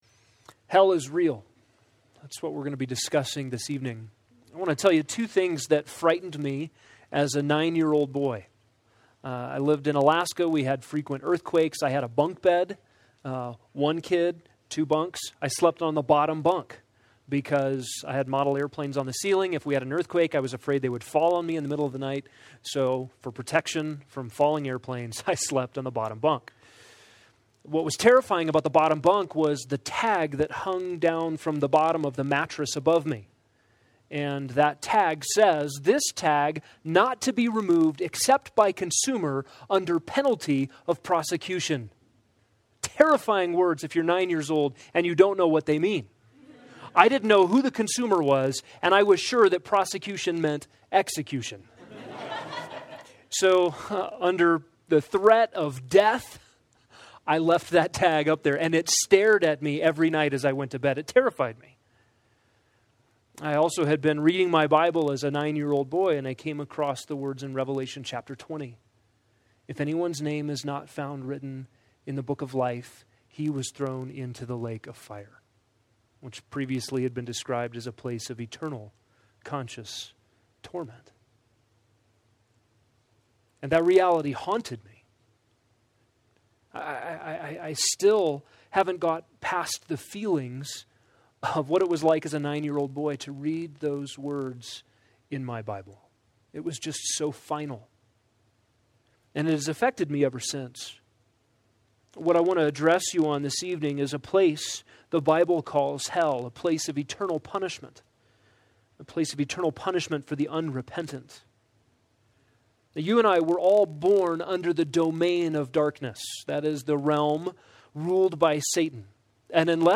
College/Roots Roots Winter Retreat - 2018 Audio ◀ Prev Series List Next ▶ Previous 2.